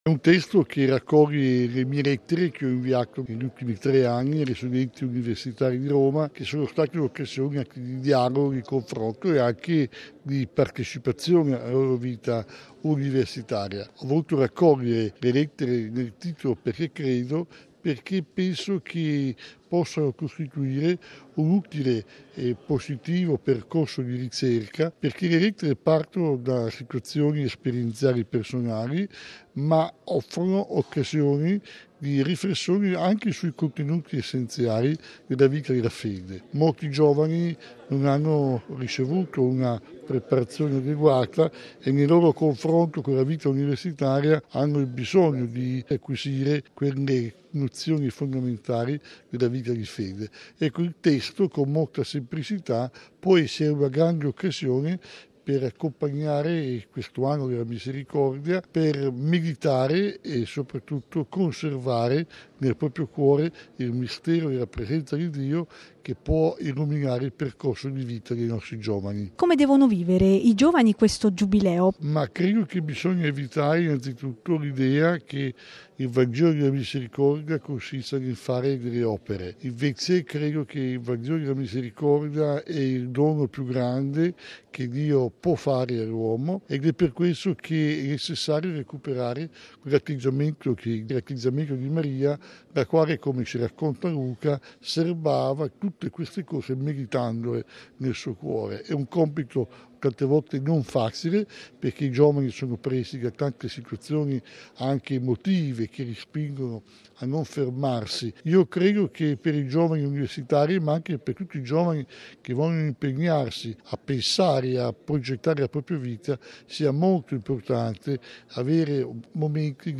L’autore al microfono